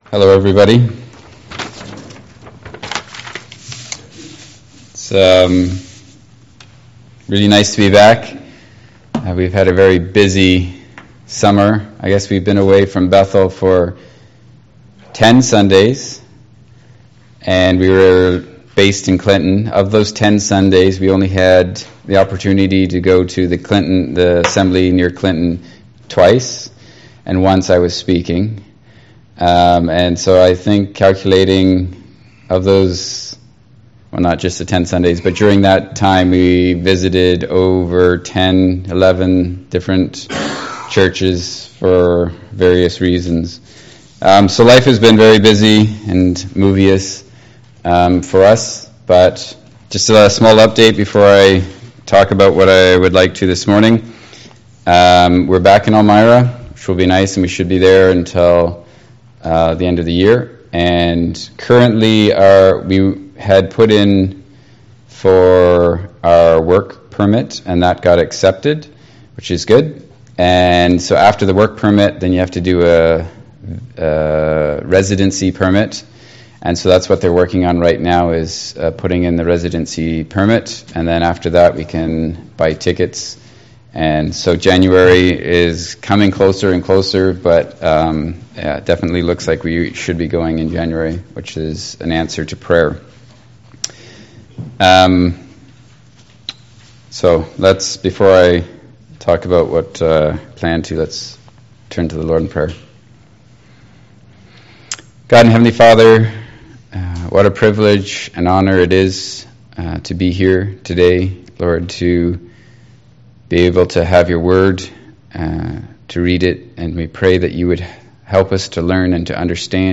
Service Type: Family Bible Hour Topics: Death , obedience , sanctification , service , sin , submission « Behold The Perfect Man